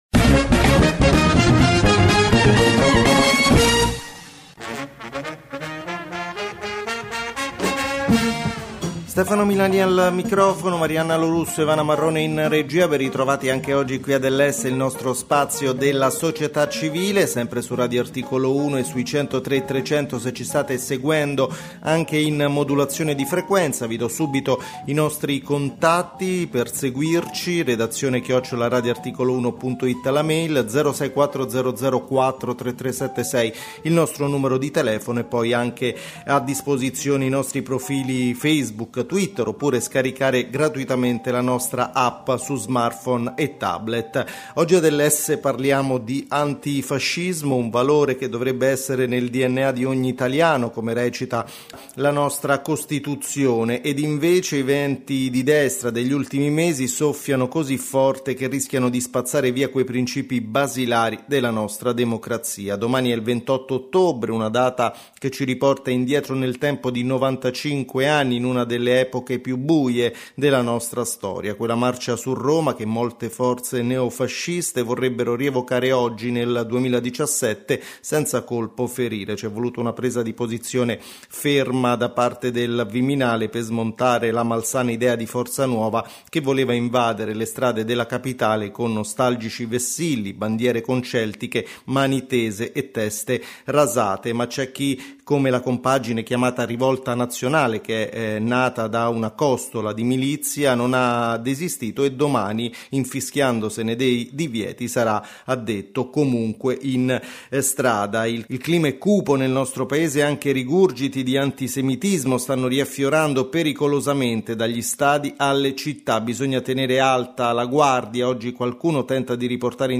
SERVIZIO DEL QUOTIDIANO ONLINE/ONAIR della CGIL